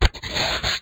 door.ogg